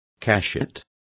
Complete with pronunciation of the translation of cachets.